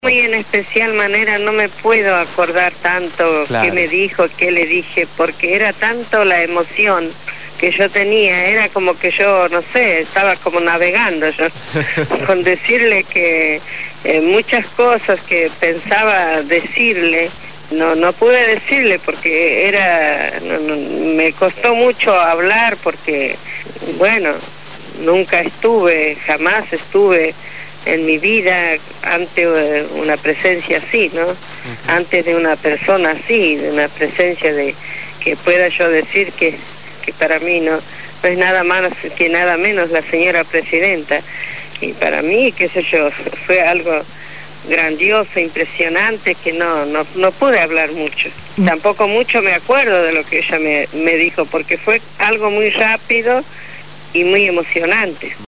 fue entrevistada en Radio Gráfica FM 89.3 durante la emisión del programa Punto de Partida